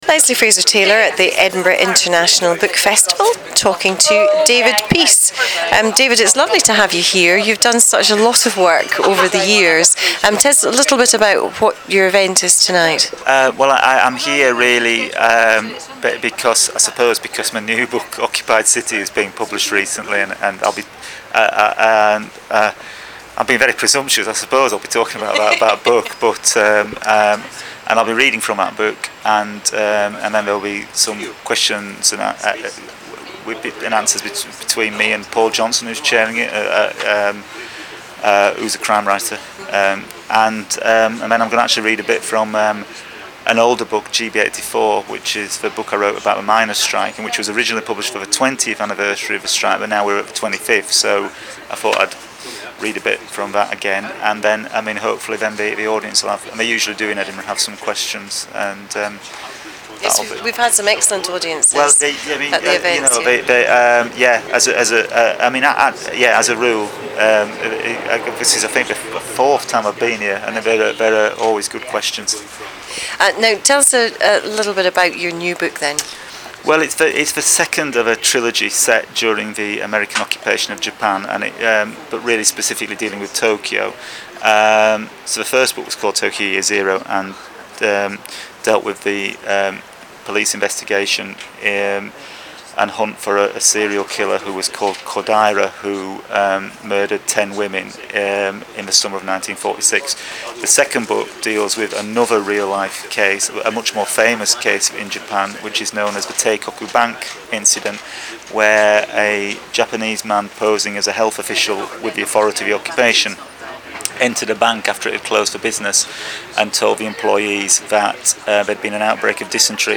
A series of podcasts and author interviews from the 2009 Edinburgh International Book Festival Festival, 15-31 August.